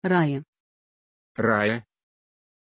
Aussprache von Raja
pronunciation-raja-ru.mp3